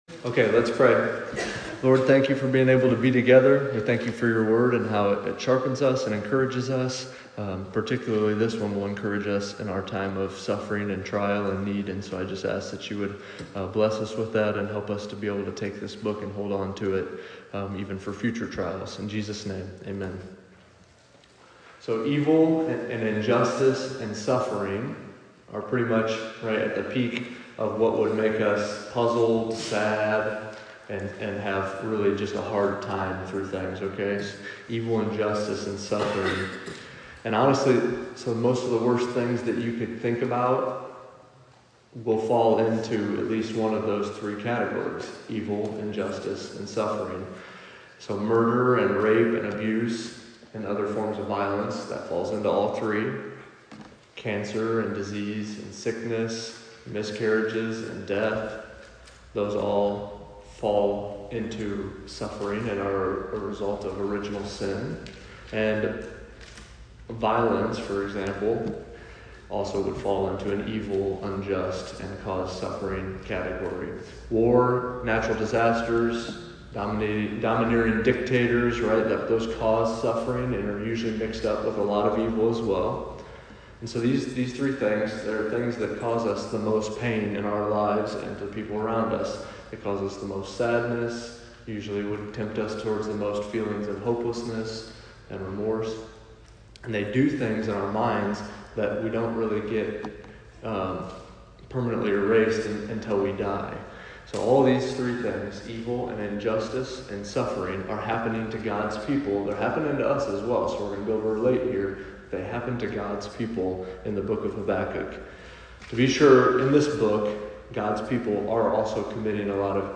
Habakkuk Survey Sermon.mp3